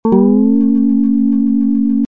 mathematical (e.g. guitar) strings simulation with audio
bend1.mp3